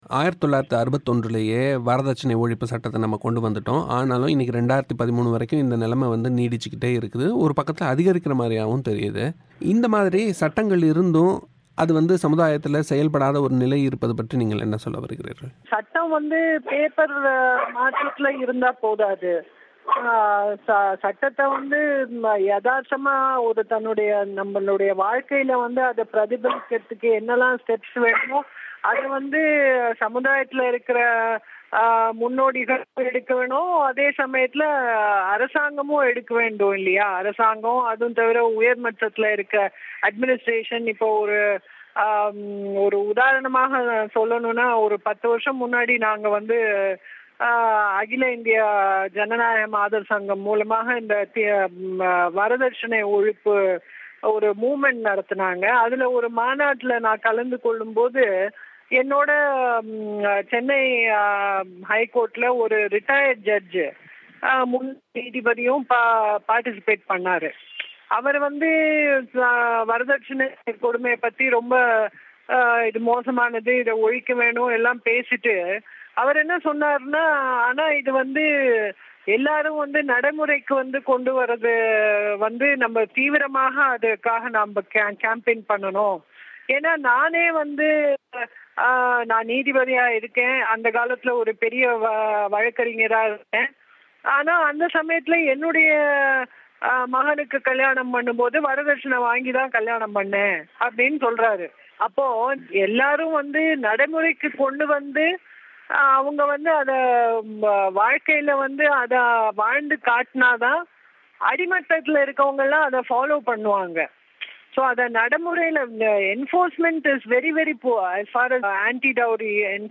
தமிழோசைக்கு வழங்கிய செவ்வி